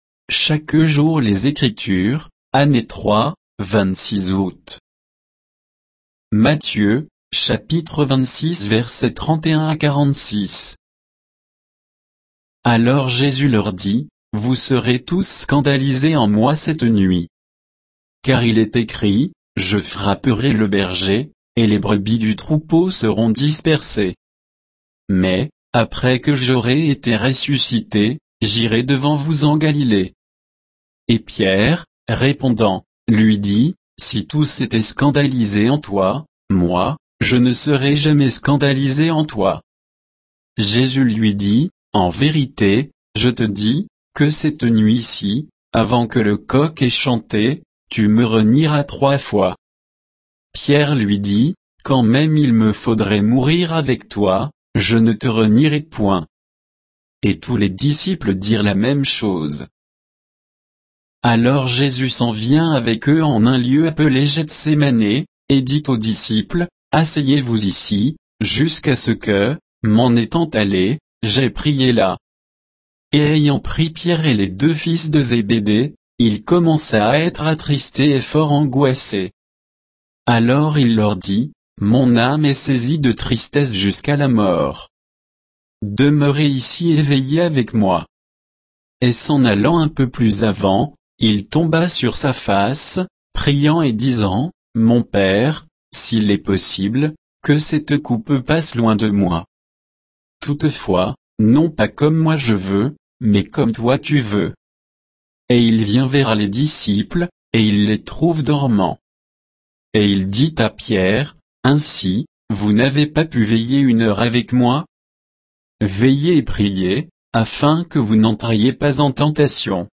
Méditation quoditienne de Chaque jour les Écritures sur Matthieu 26